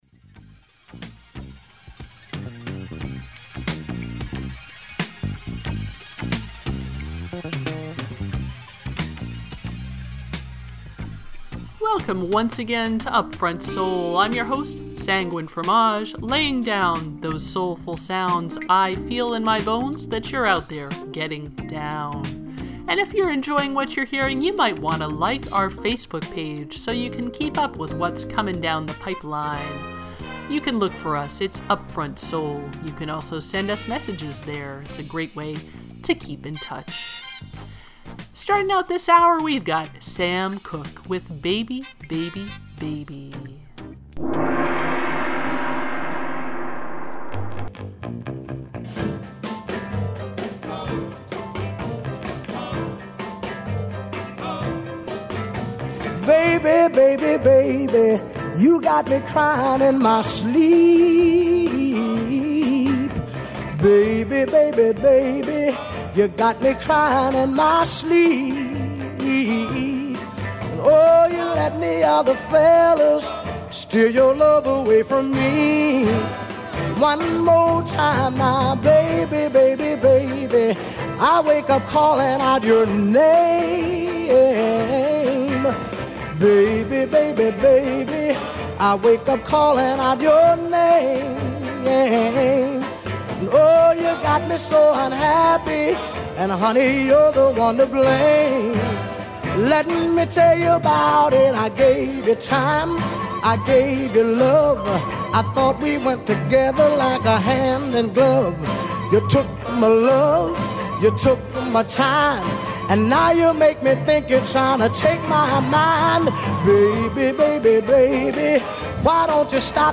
Funk, soul, and jazz
120 minutes of soulful sounds to which you may get down. Program Type: Weekly Program Speakers